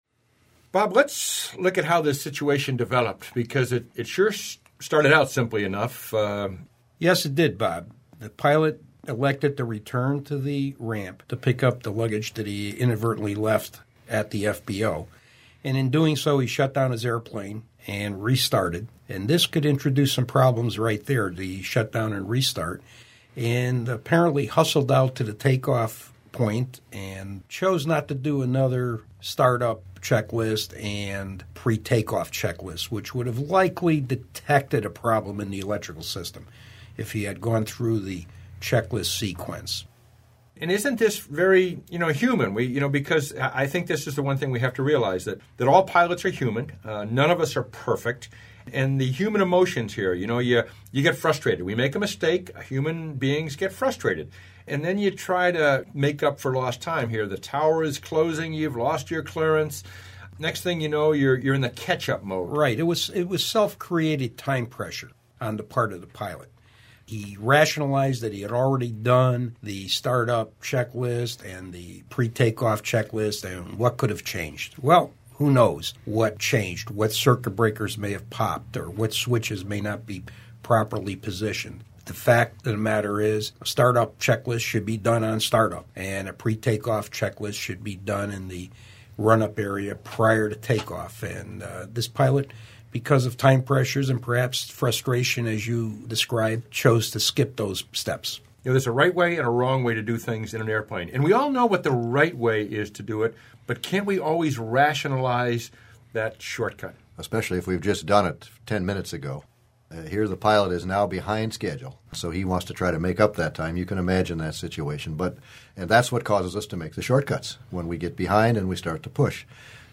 Listen in as our team of instructors discuss and debate the details of this scenario.